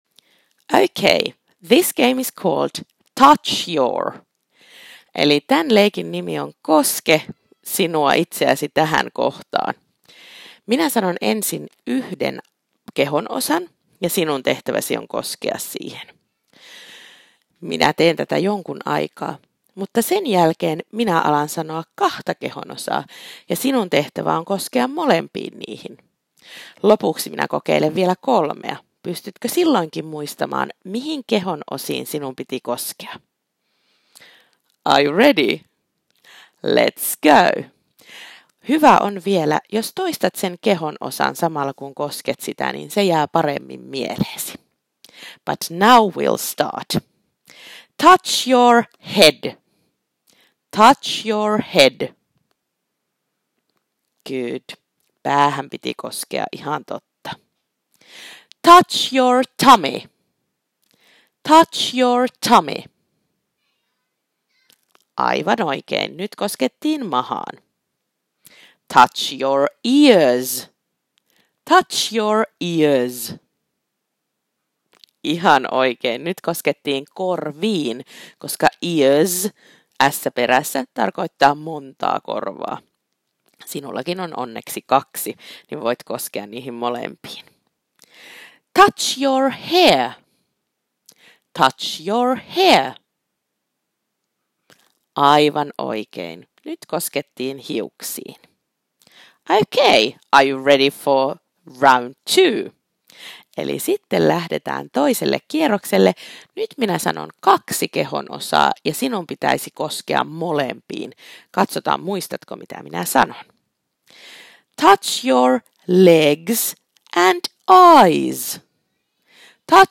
* Kuuntele opettajan puhetta ja toimi ohjeen mukaan!